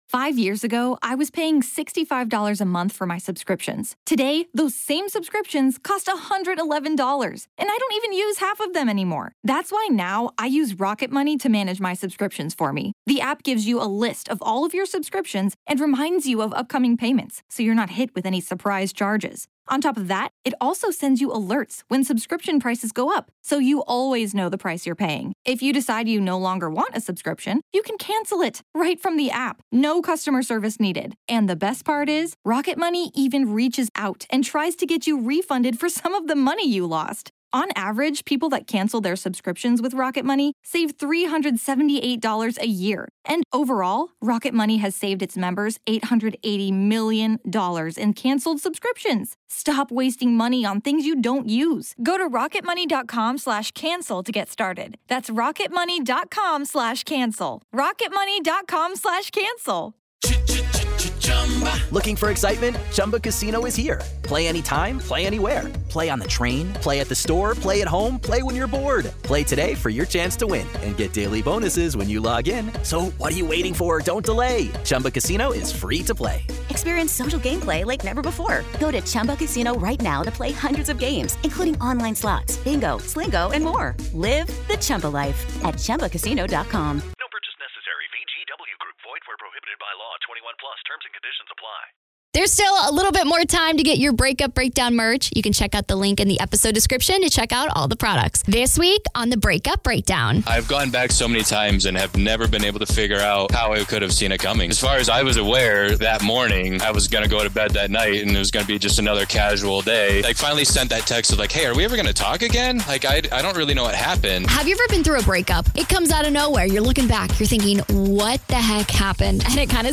When it was our first male interview.... - S2E16